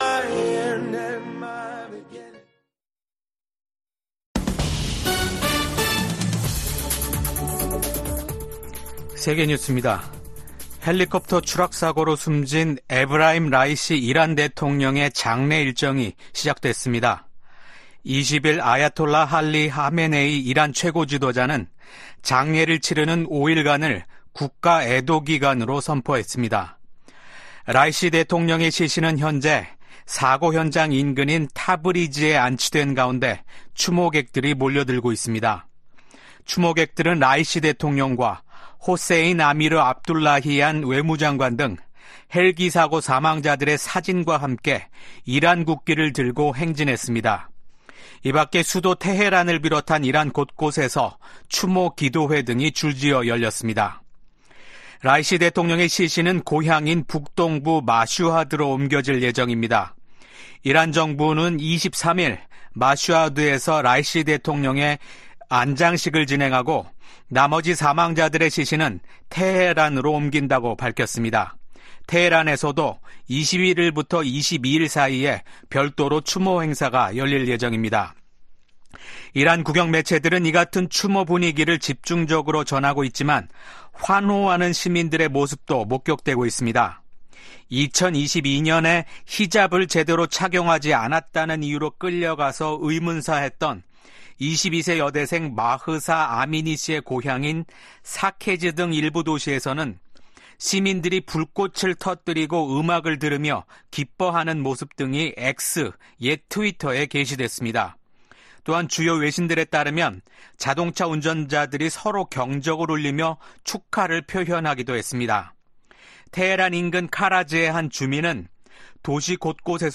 VOA 한국어 아침 뉴스 프로그램 '워싱턴 뉴스 광장' 2024년 5월 22일 방송입니다. 최근 북한과 러시아의 협력 강화는 중국도 우려해야 할 사안이라고 미 국무부가 지적했습니다. 미국이 유엔 무대에서 북한과 러시아 간 불법 무기 이전을 비판하면서, 서방의 우크라이나 지원을 겨냥한 러시아의 반발을 일축했습니다. 유럽연합 EU는 러시아가 중국과의 정상회담 후 북한 옹호성명을 발표한 데 대해 기회주의적인 선택이라고 비판했습니다.